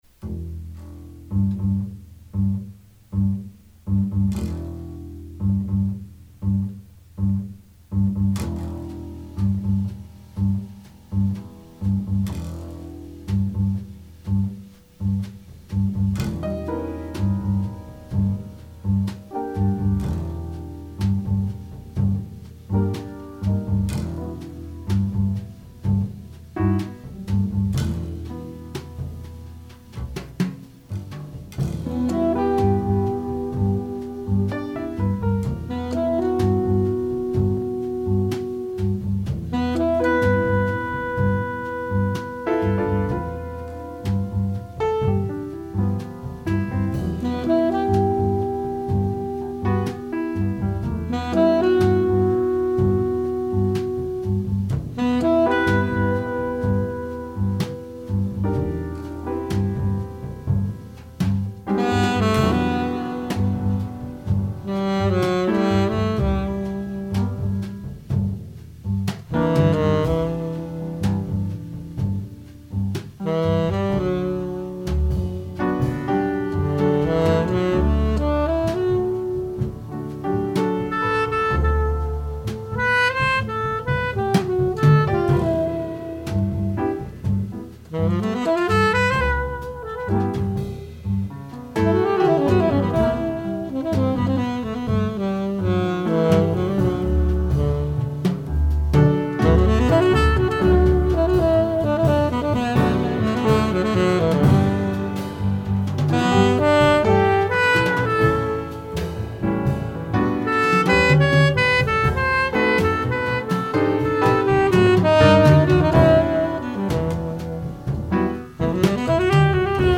is mature, introspective and original.